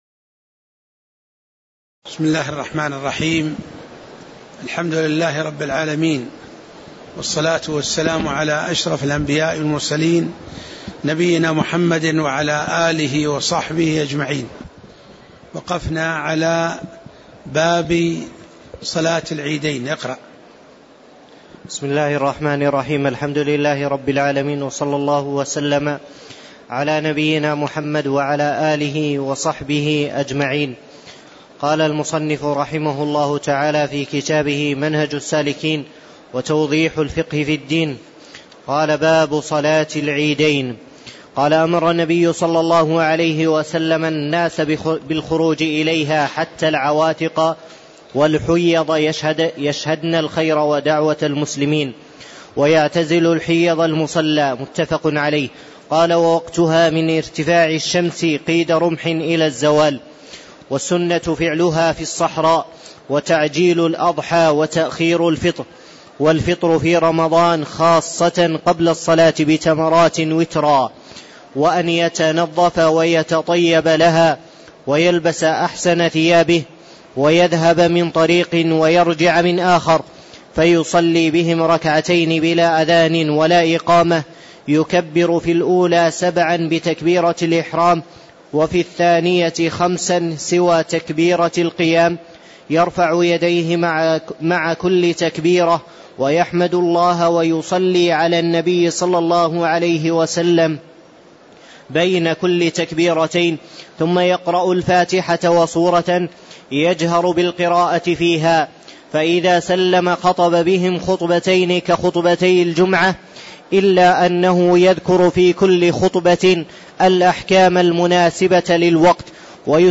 تاريخ النشر ١٥ شوال ١٤٣٧ هـ المكان: المسجد النبوي الشيخ